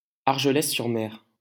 Fichier audio de prononciation du projet Lingua Libre